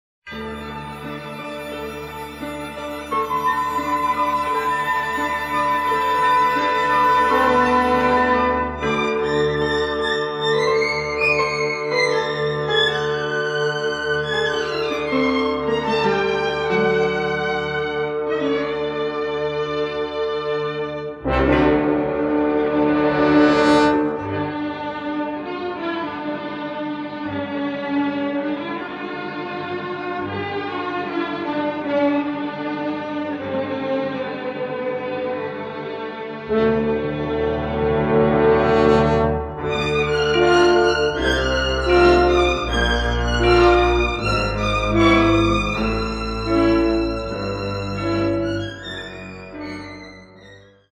the Czardas